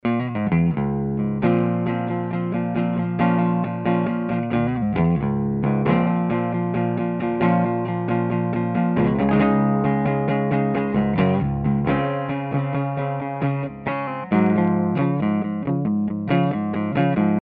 Electric archtops comparison
A bit too loud for home playing.
Maybe not the best detailed and pristine sounding mike.
The signal was routed directly into a Digi 001 mic preamp, not specially good, but nothing else available at this moment. These clips are raw and untreated in any way (no Eq, no Fx, No compression or anything).
Note the evident output differences.
Gibson P13 pickup